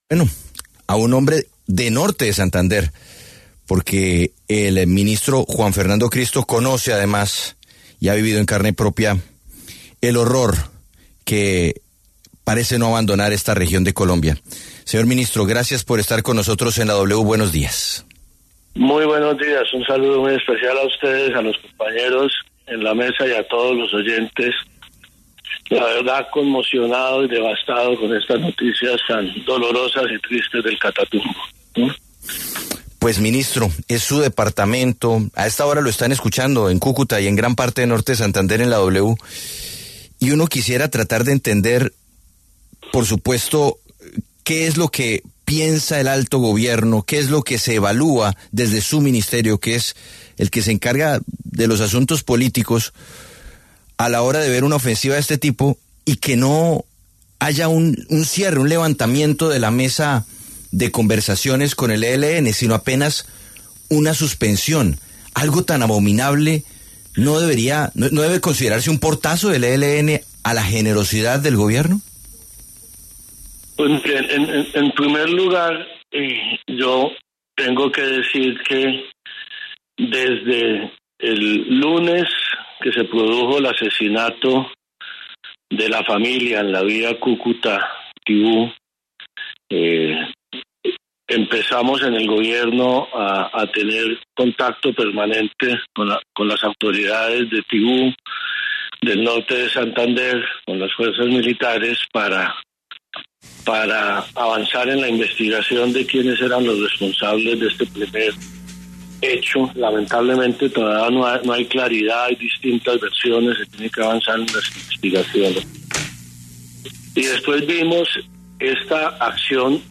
En diálogo con La W, el ministro Juan Fernando Cristo dijo que el ELN está cometiendo un crimen de guerra por la ola de violencia desatada en el Catatumbo.